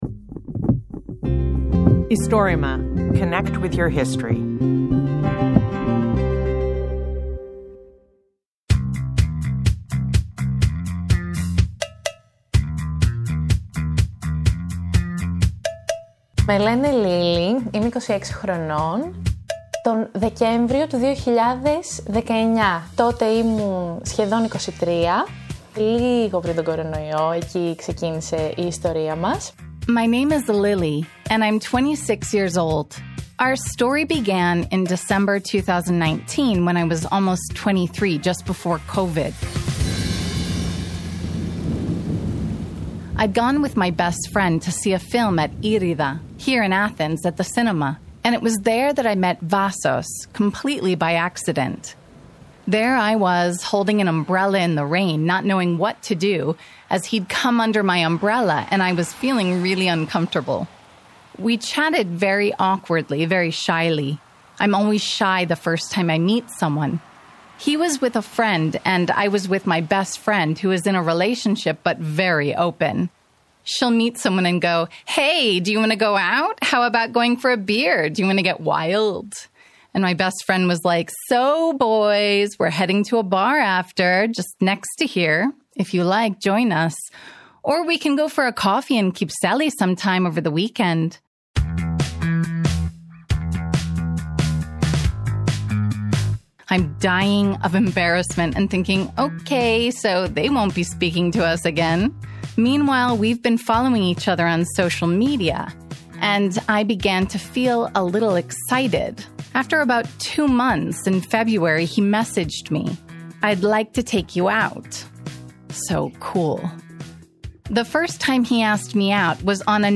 Istorima is the bigest project of recording and preserving oral histories of Greece.